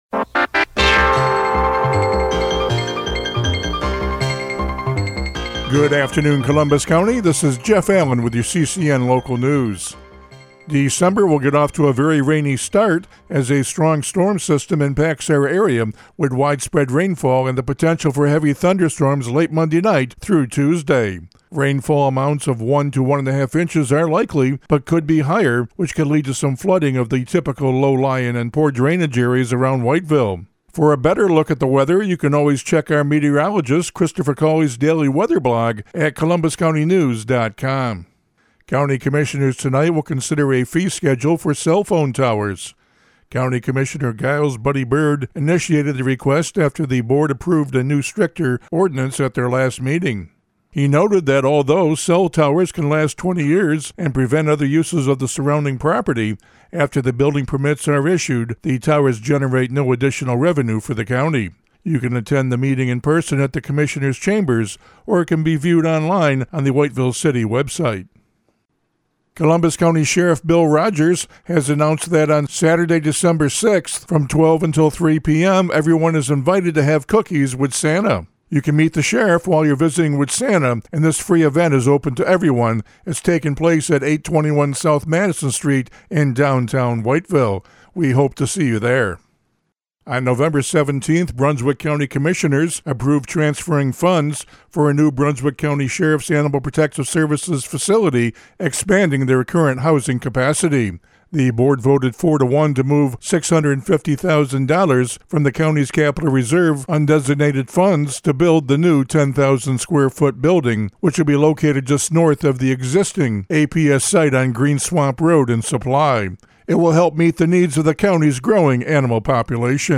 CCN Radio News — Afternoon Report for December 1, 2025
CCN-AFTERNOON-NEWS-REPORT.mp3